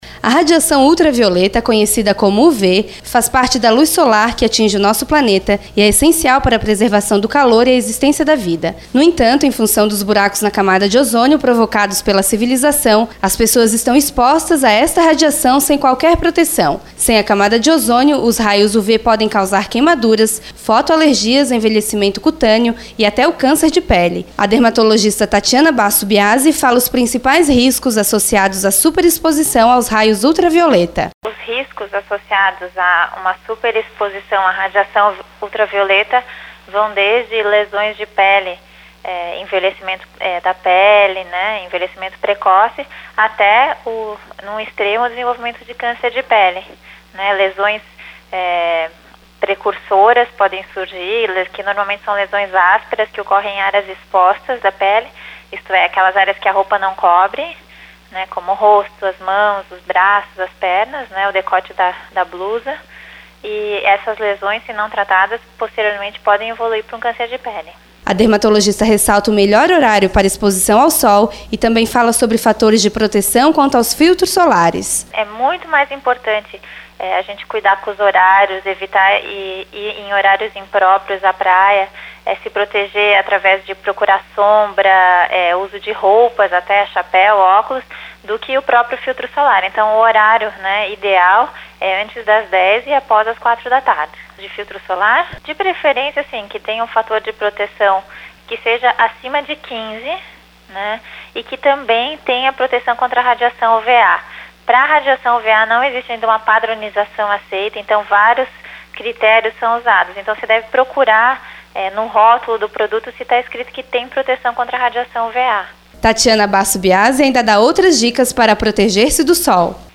Nesta entrevista para a Rádio Guarujá